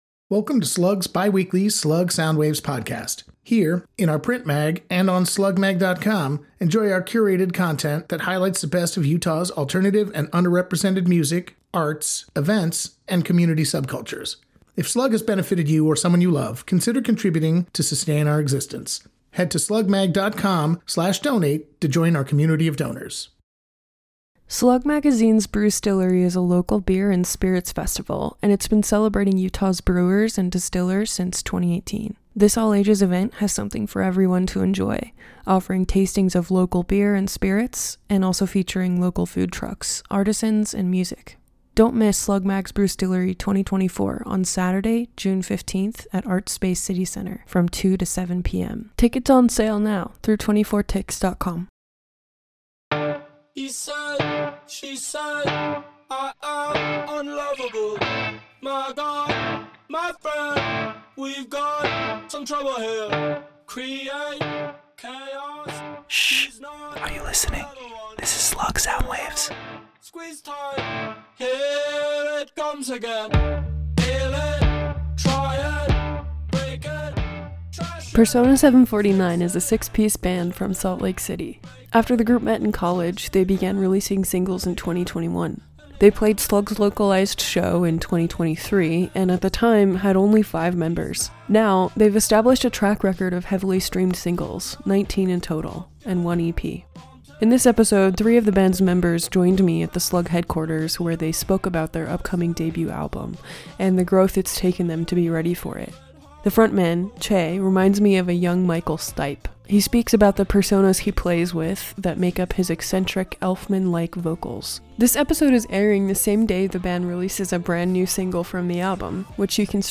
In this episode, PERSONA 749 members joined me at the SLUG headquarters where they spoke about their upcoming debut album.